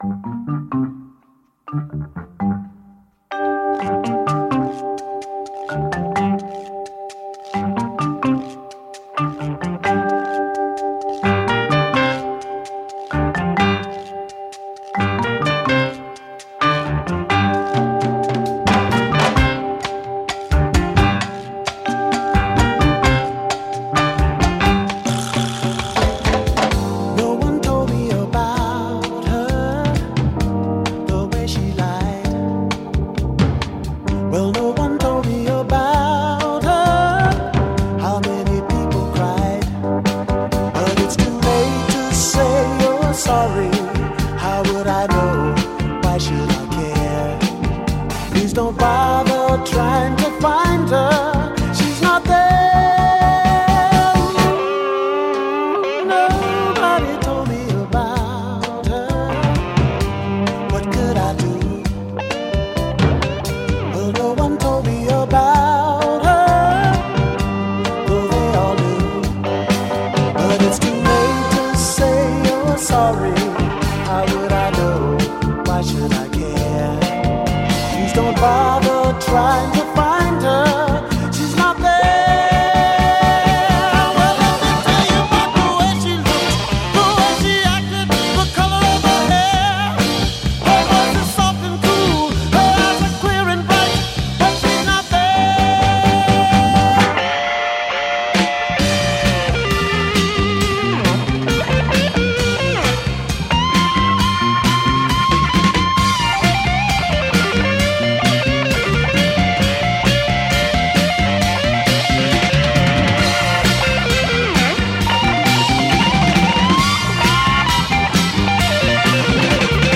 Guitars
Vocals
Bass
Keys
Drums
Percussion